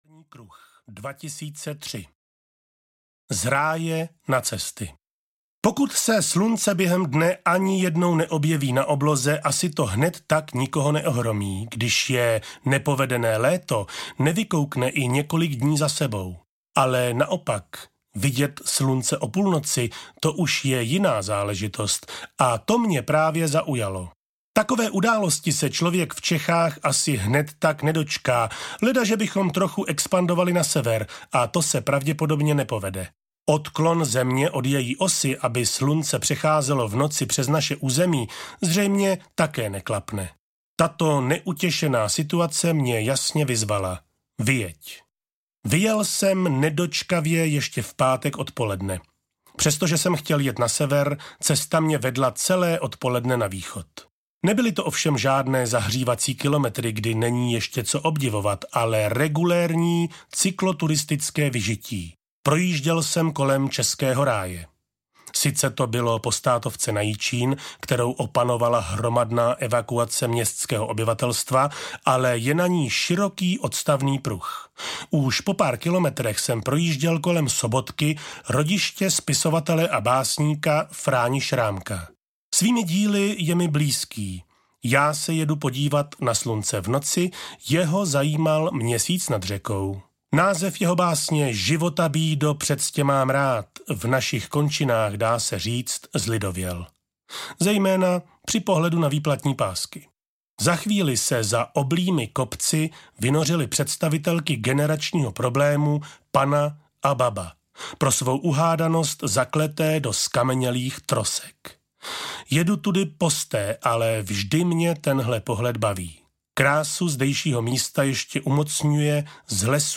Kolmo na Moskvu audiokniha
Ukázka z knihy